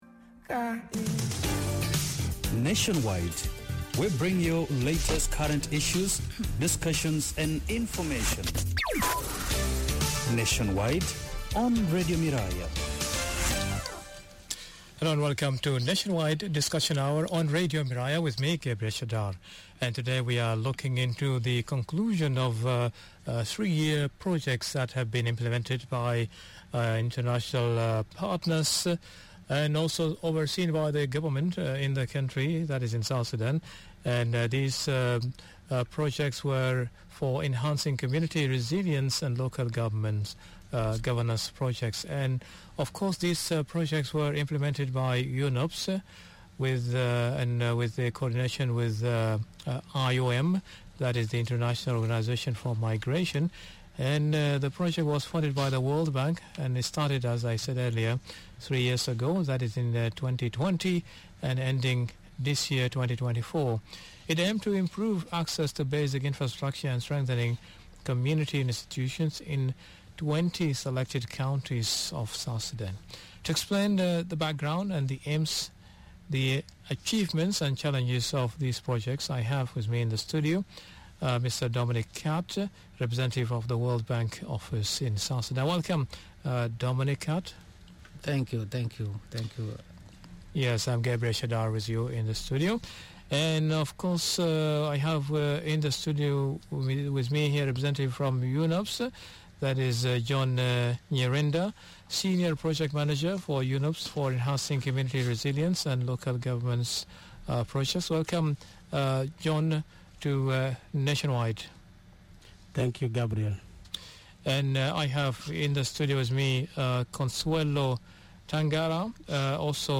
Radio Miraya's in conversation with: 1.